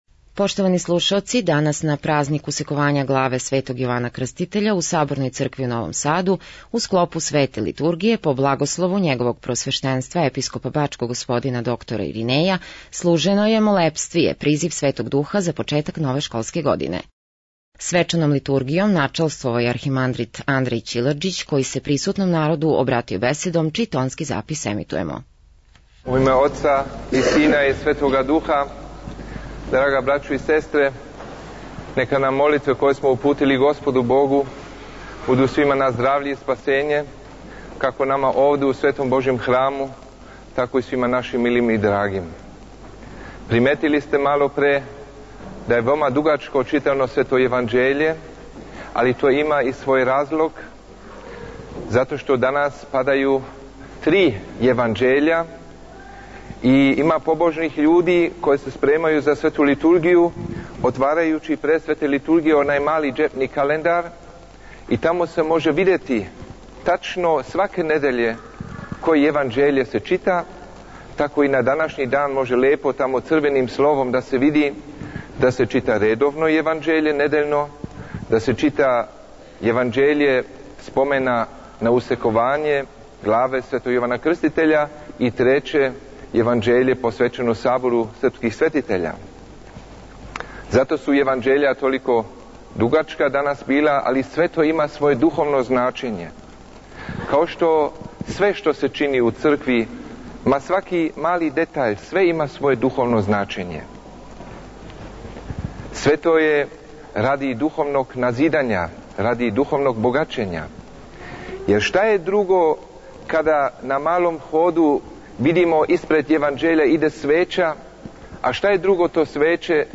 Поводом празника Усековањa главе Светог Јована Крститеља, 11. септембра 2011. године, у Саборној цркви Светог великомученика и победоносца Георгија у Новом Саду, уз учешће многобројног верног народа служена је света Литургија којом је свештеноначалствовао високопреподобни архимандрит Андреј Ћилерџић, новоизабрани Епископ ремезијански.